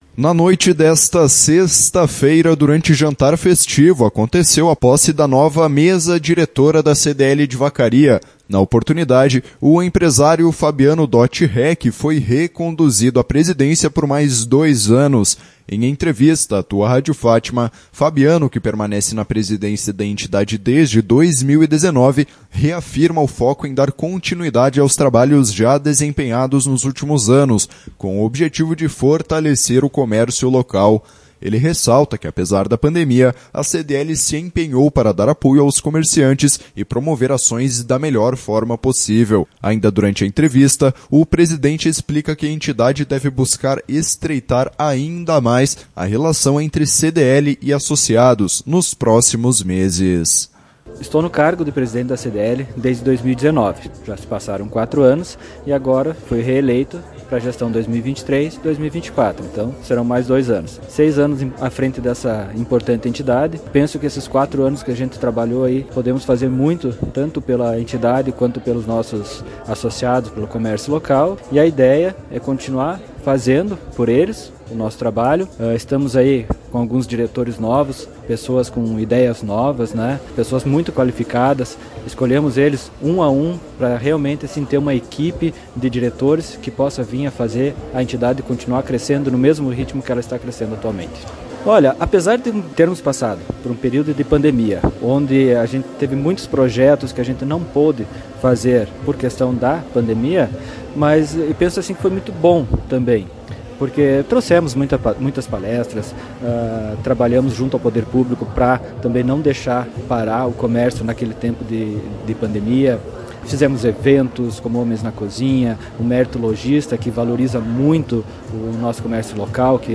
Ele ressalta que, apesar da pandemia, a CDL se empenhou para dar apoio aos comerciantes e promover ações da melhor forma possível.